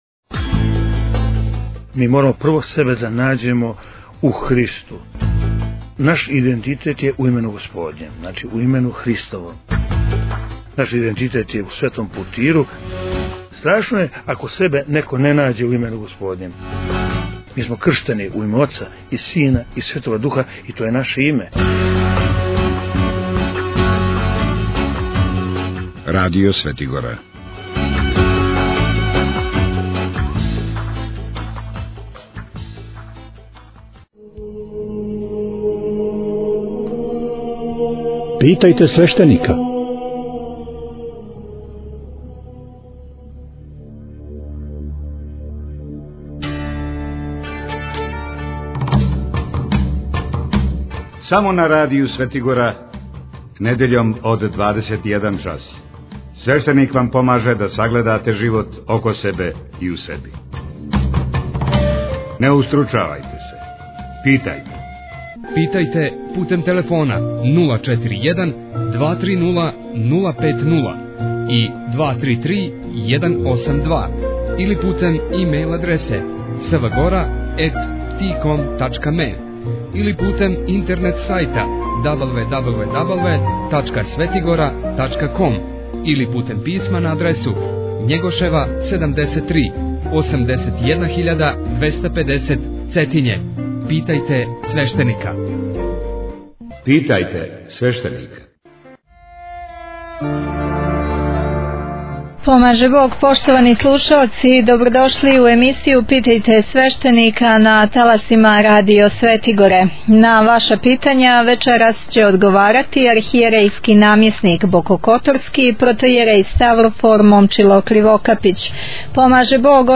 MP3 Mono 11kHz 20Kbps (VBR)